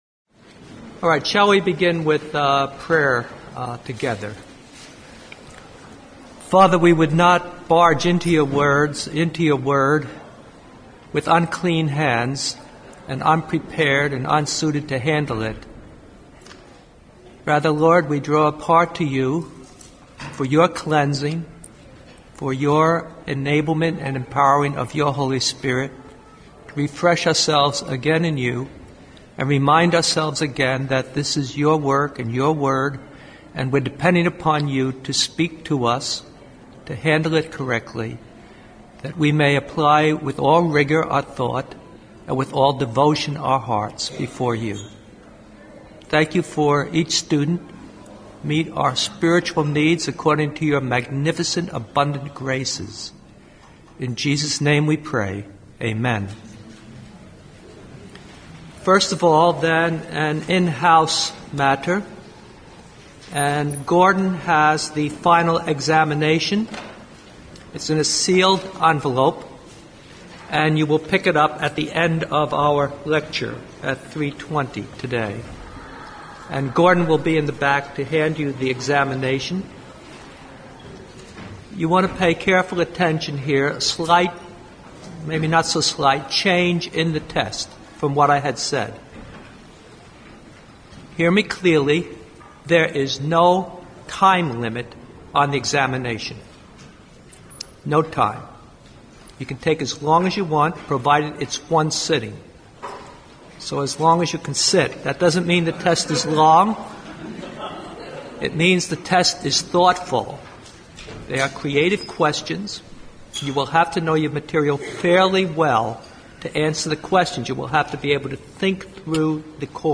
Introduction to the Old Testament Lesson